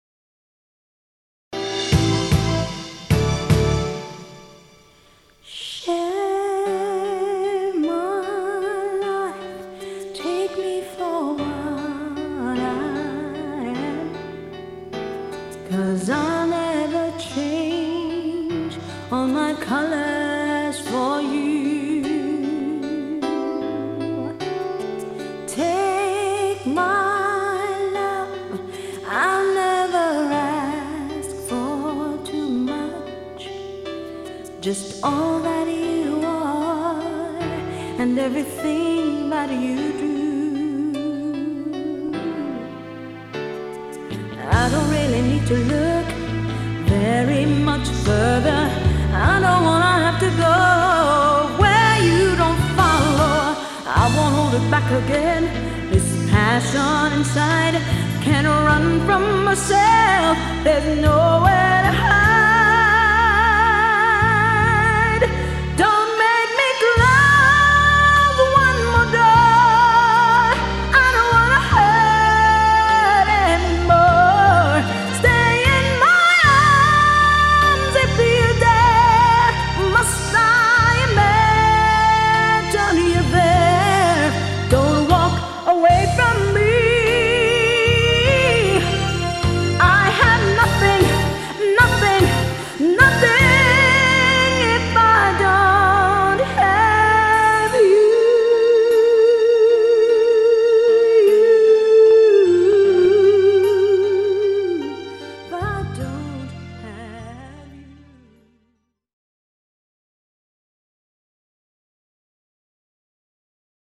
Wedding Singer